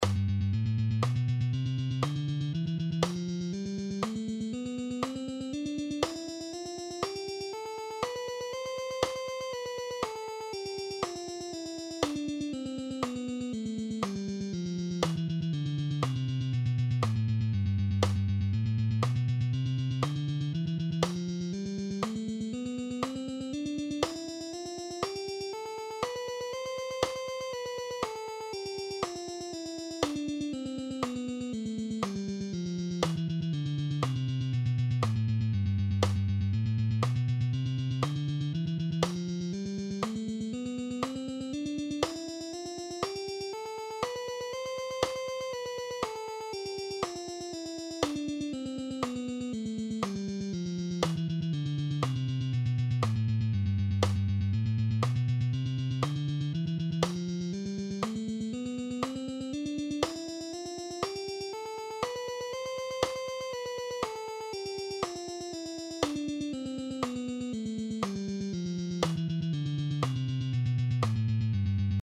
All these guitar exercises are in ‘C’
Mixolydian Tremolo Picking Guitar Lesson
5.-Mixolydian-Tremolo-Picking-Guitar-Lesson.mp3